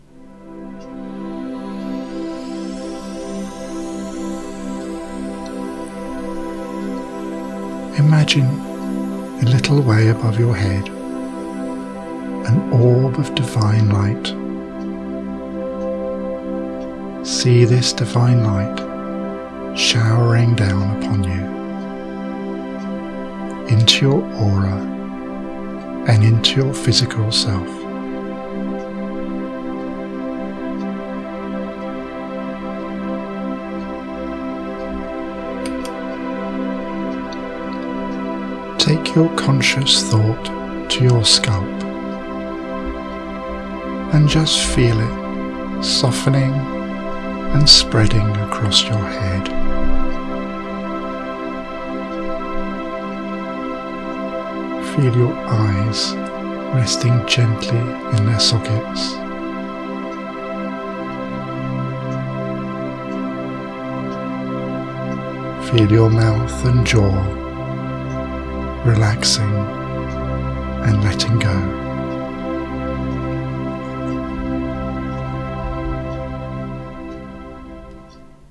Joy in your Heart is my second guided meditation CD, released in June 2016 and the follow up to Journey to Inner Peace.